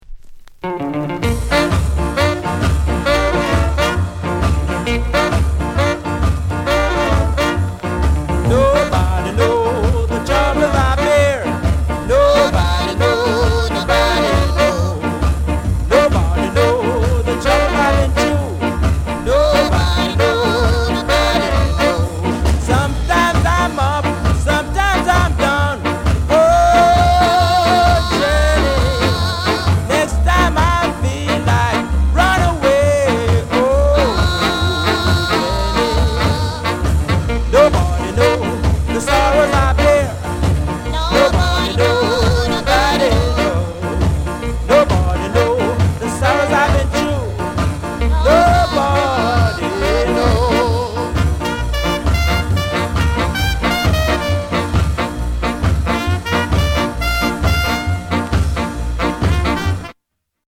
BIG SKA INST